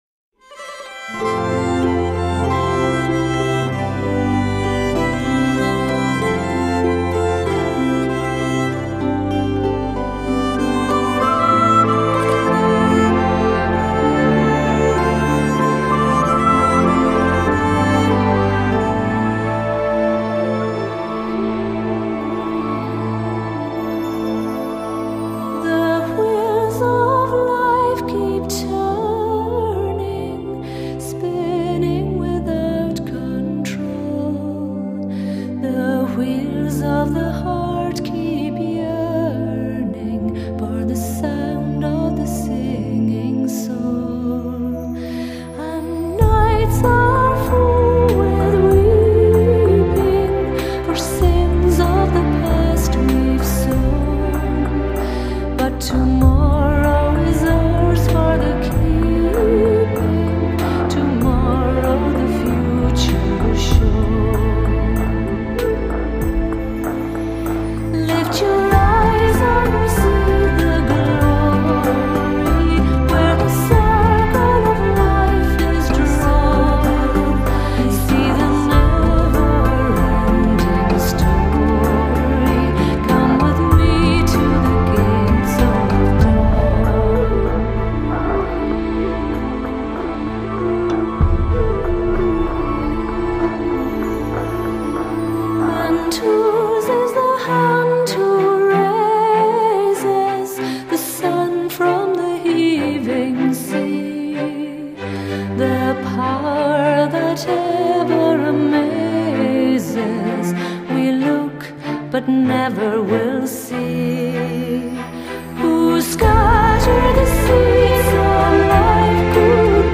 低沉耐人寻味专辑延续了小提琴和钢琴在乐曲中的主导地位和对古朴叙事歌谣的表现力
融合了爱尔兰空灵飘渺的乐风，挪威民族音乐及古典音乐
乐曲恬静深远，自然流畅。